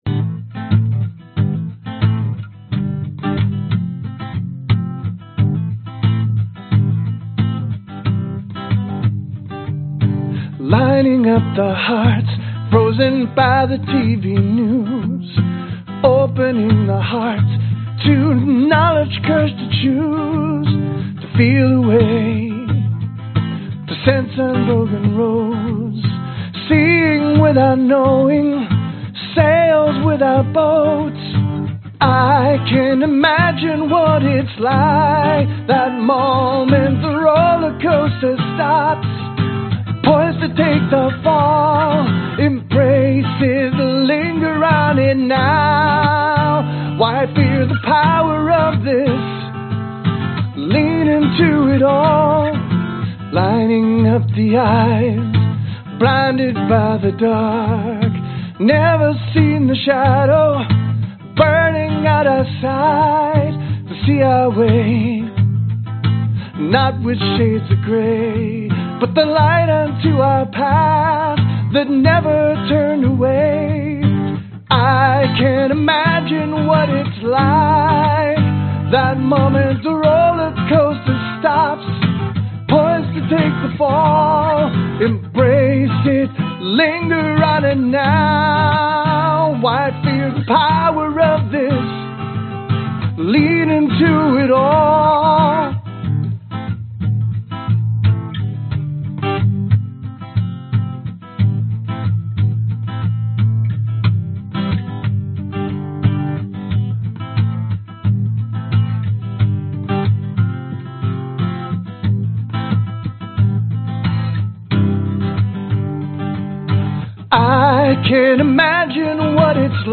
标签： male_vocals original_song spiritual
声道立体声